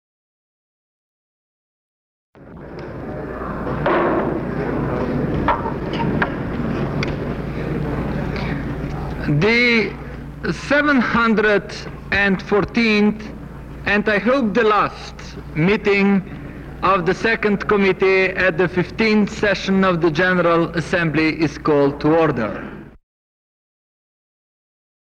United Nations Chairman Janez Stanovnik opens the 714th session of the Economic and Financial Committee